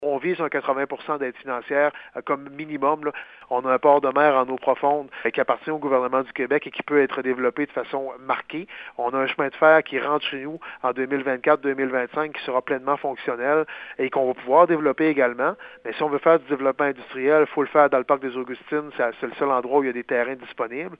Daniel Côté, explique qu’il faut dépenser environ 200 000$ pour réaliser cette étape qui déterminera le tracé et les coûts de construction de la route: